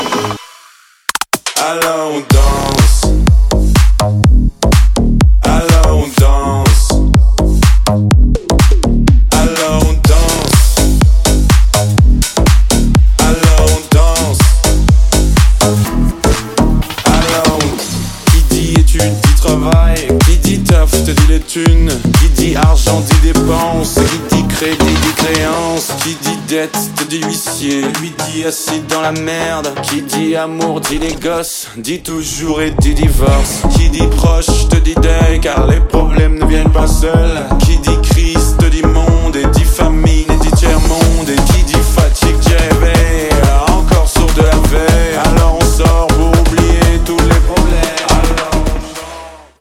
мужской голос
remix
deep house
Electronic
EDM
Стиль: deep house